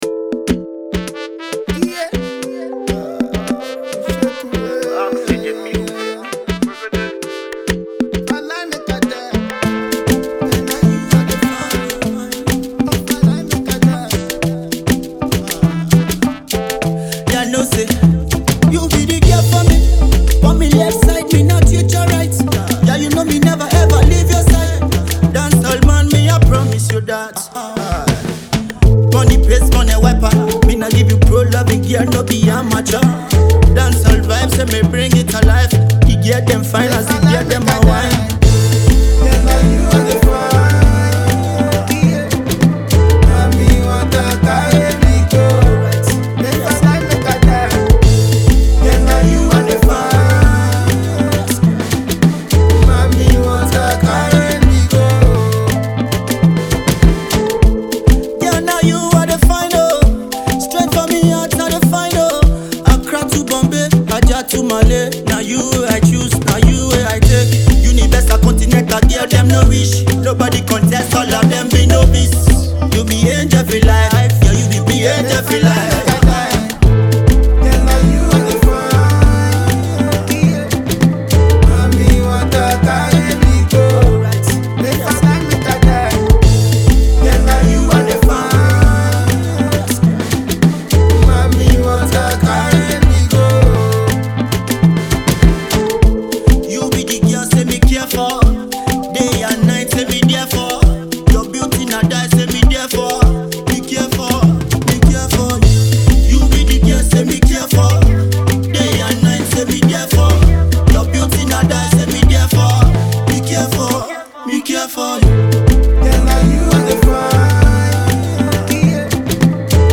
Fast-rising Nigerian dancehall artist